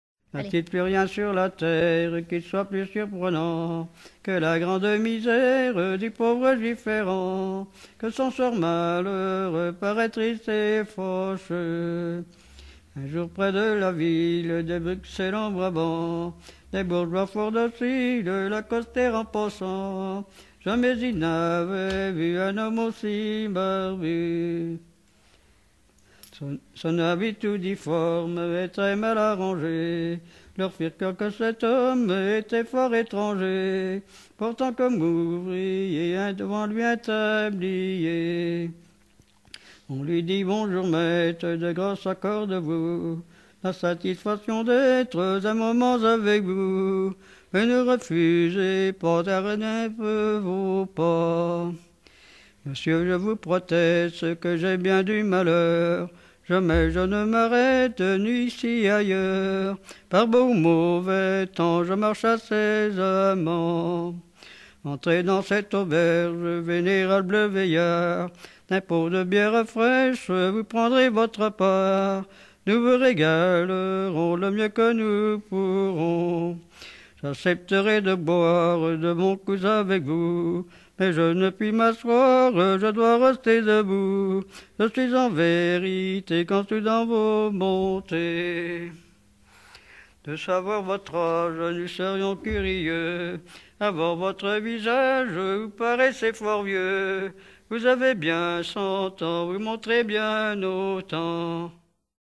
Localisation Cugand
Genre strophique